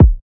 short kick.wav